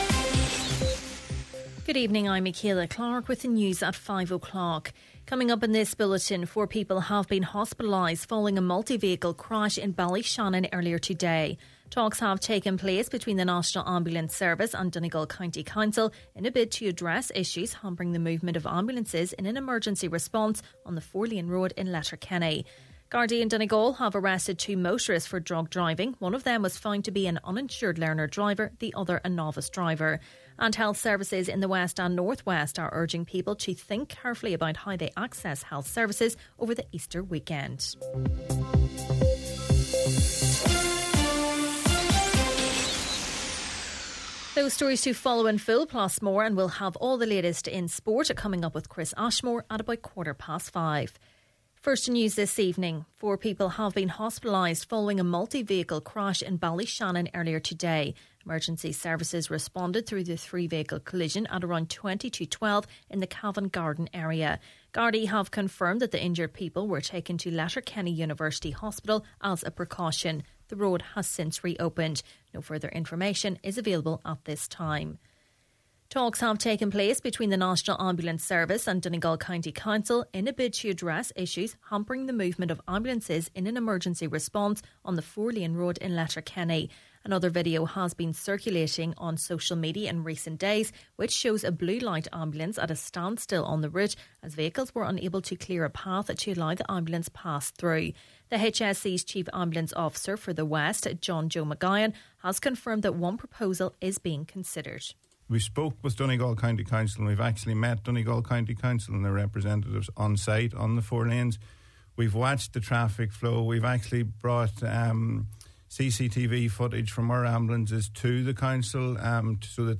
Main Evening News, Sport and Obituaries – Wednesday, April 16th